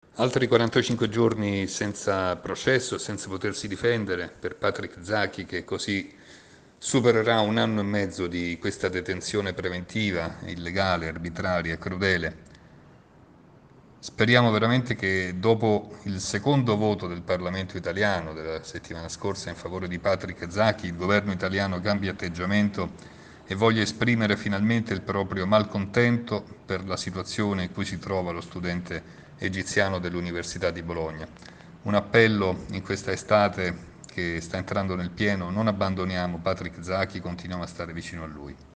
Ai nostri microfoni